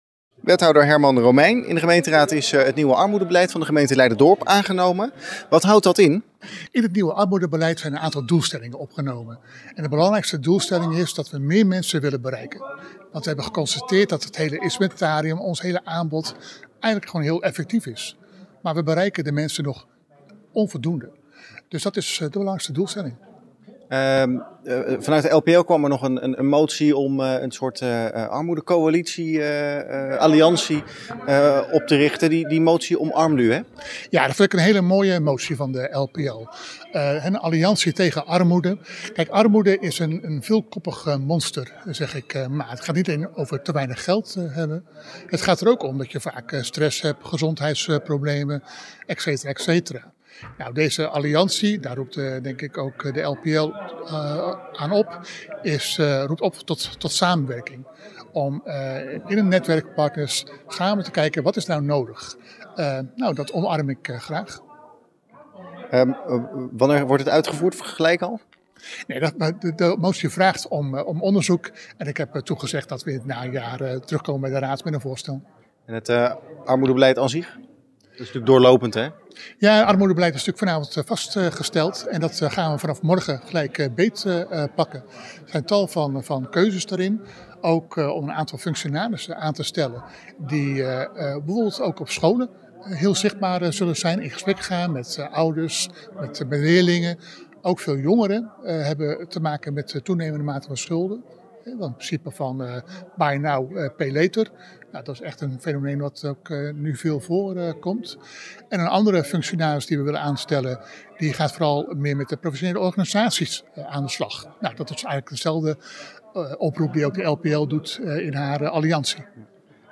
Wethouder Herman Romeijn over nieuw armoedebeleid.
Wethouder-Herman-Romeijn-over-nieuw-armoedebeleid.mp3